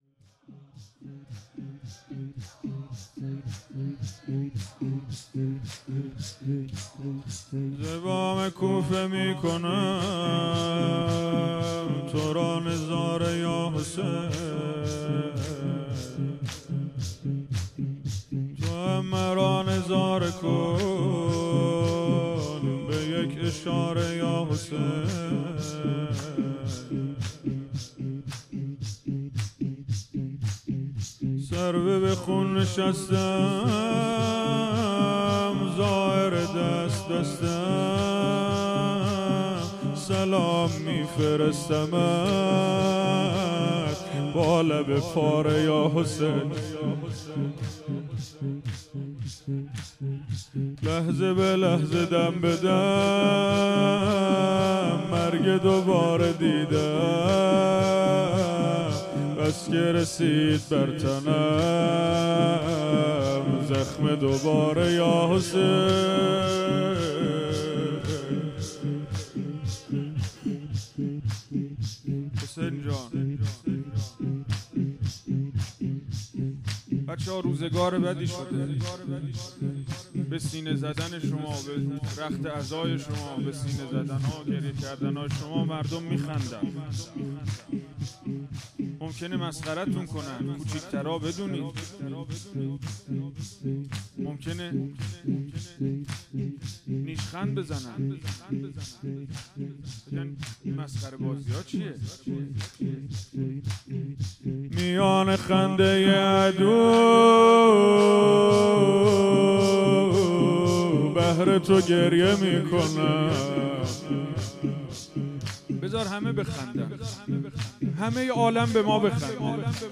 هیئت حسن جان(ع) اهواز
دهه اول محرم الحرام ۱۴۴۴